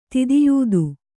♪ tidiyūdu